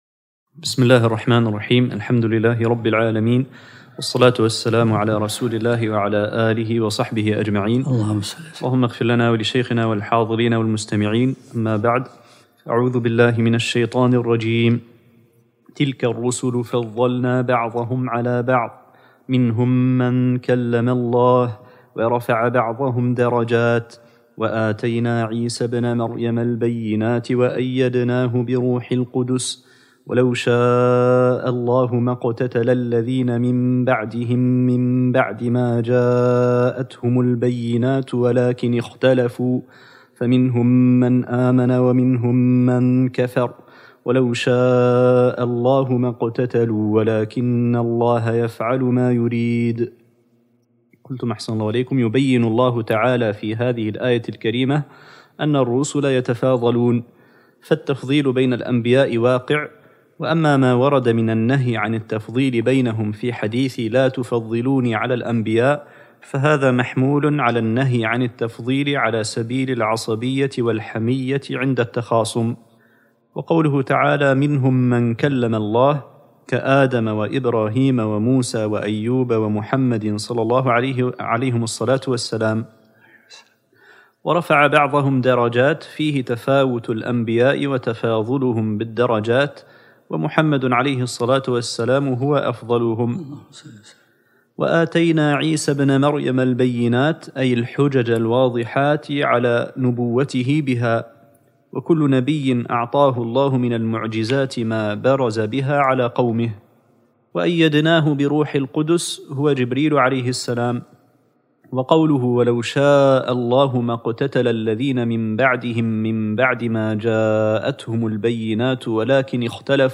الدرس العشرون من سورة البقرة